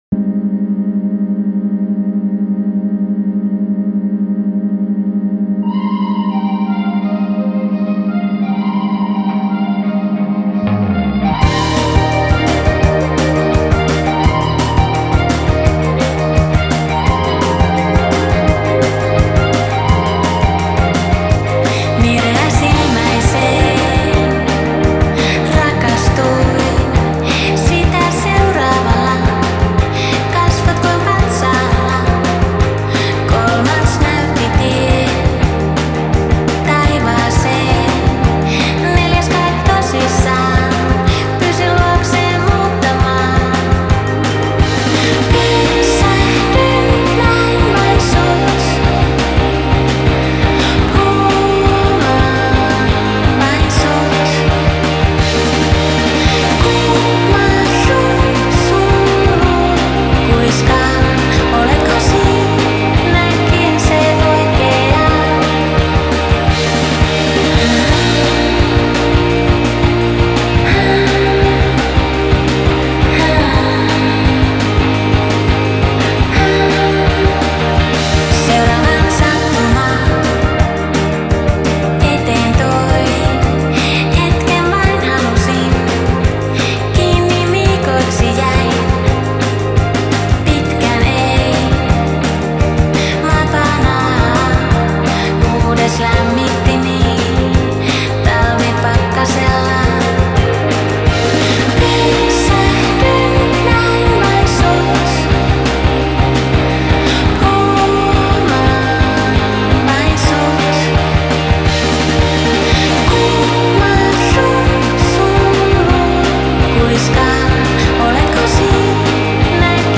Style: Dream Pop